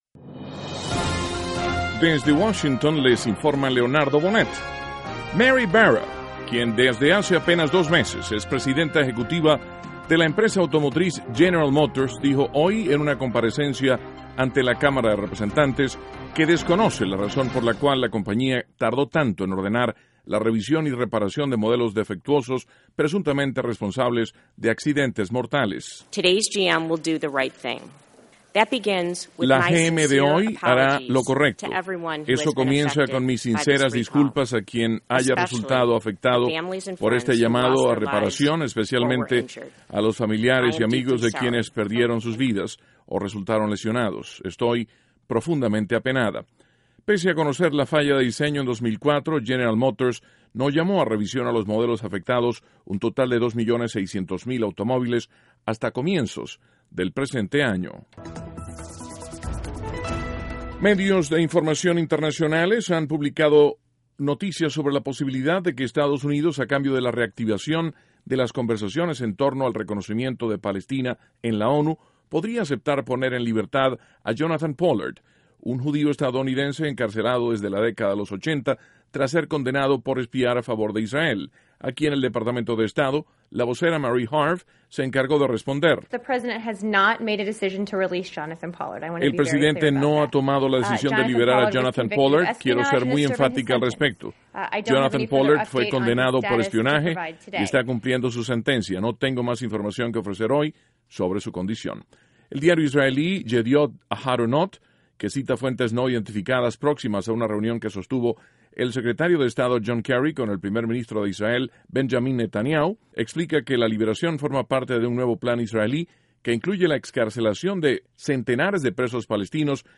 NOTICIAS - MARTES, PRIMERO DE ABRIL, 2014
(Sonido – Barra) 2.- Departamento de Estado ratifica que el presidente Obama no ha ordenado excarcelación del ex espía israelí, Jonathan Pollard. 3.- El presidente Obama recibe, en la Casa Blanca, al equipo Medias Rojas de Boston, campeones de Serie Mundial de Béisbol 2013. (Sonido Obama)